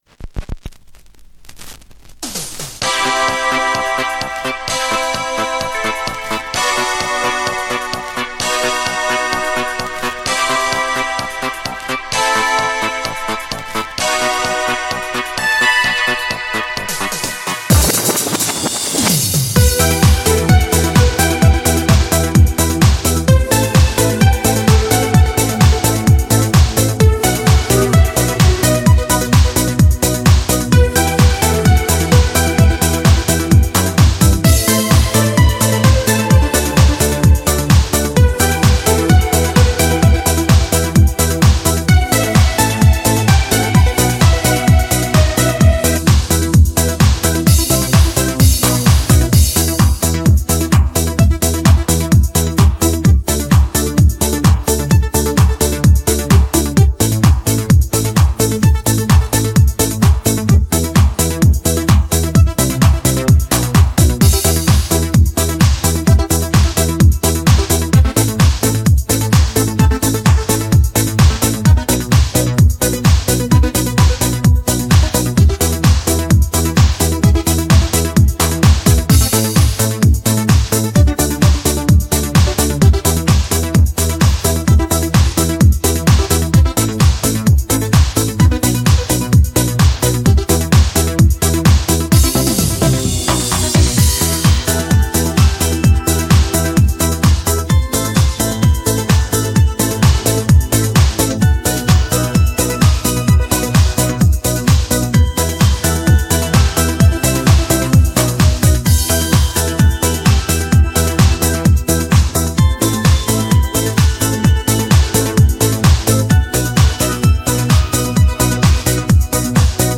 Качественный минус